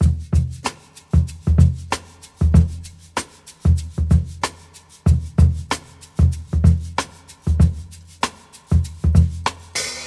• 95 Bpm Drum Loop Sample E Key.wav
Free drum beat - kick tuned to the E note. Loudest frequency: 651Hz
95-bpm-drum-loop-sample-e-key-kd6.wav